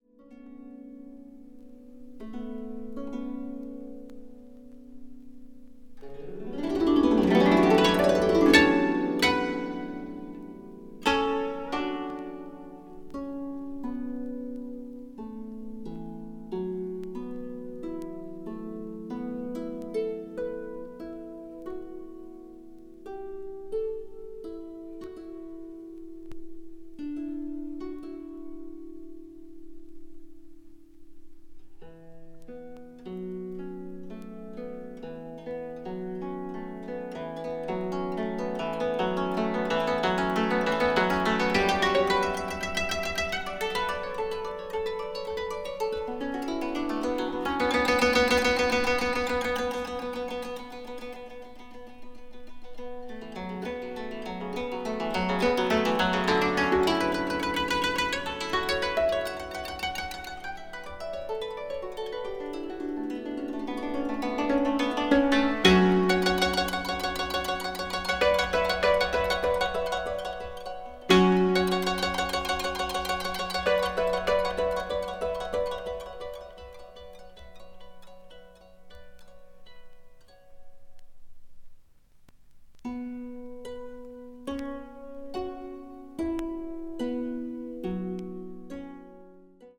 " is a four-part solo for a 20-string koto